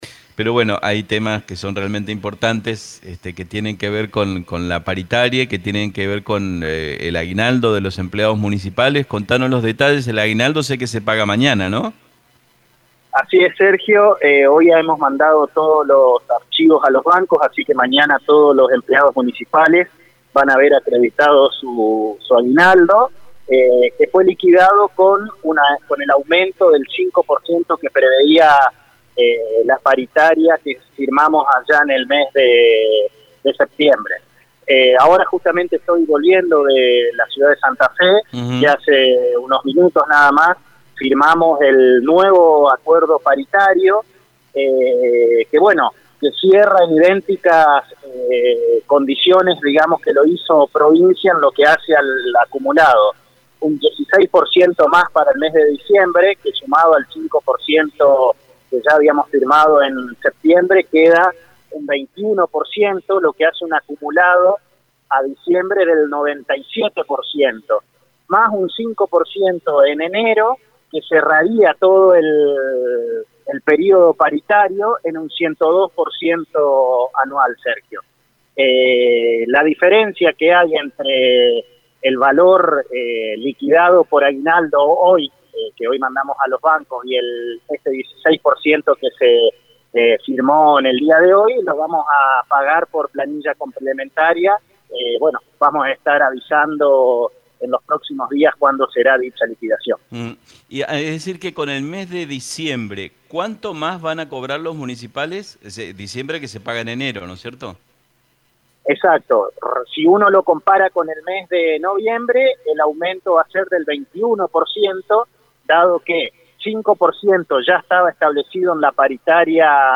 Dialogamos con el contador Matías Massat el secretario de Hacienda de la Municipalidad de Reconquista y nos confirmo este nuevo aumento paritario, idéntico valor que la oferta que hizo provincia a los estatales provinciales.